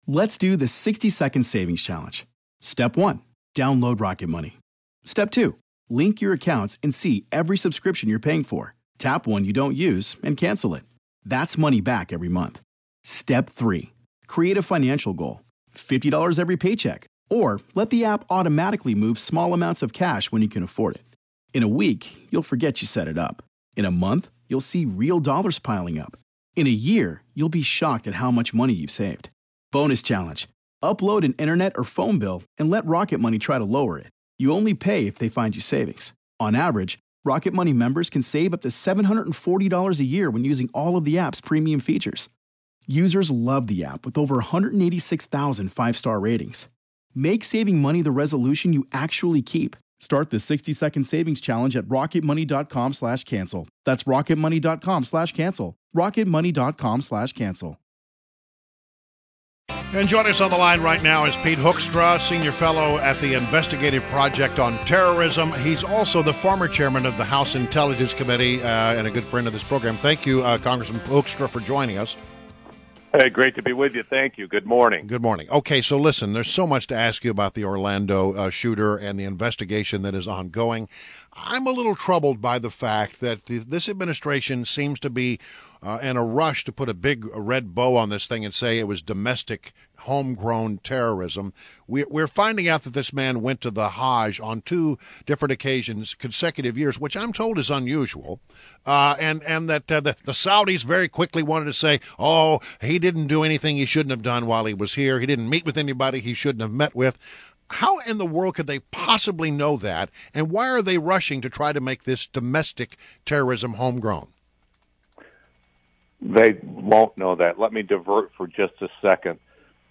WMAL Interview - REP. PETE HOEKSTRA - 06.16.16
INTERVIEW –PETE HOEKSTRA – (hook stra) – is the Senior Fellow at the Investigative Project on Terrorism and the former Chairman of the U.S. House Intelligence Committee.